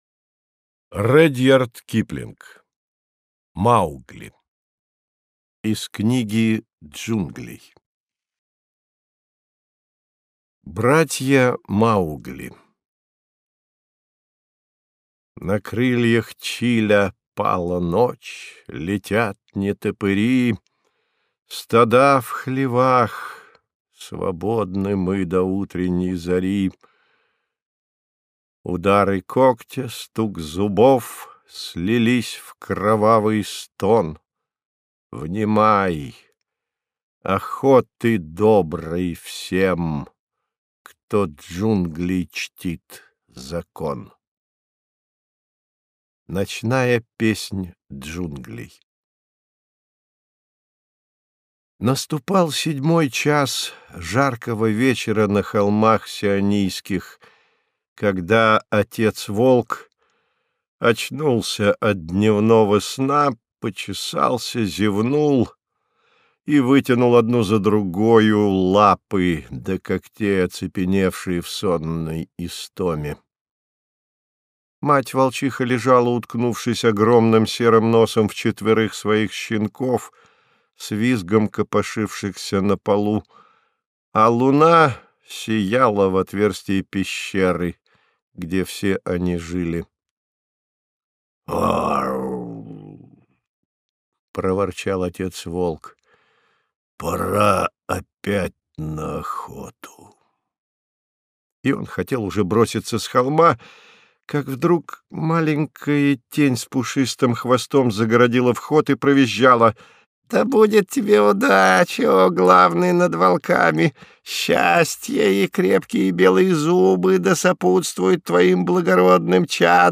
Аудиокнига Маугли | Библиотека аудиокниг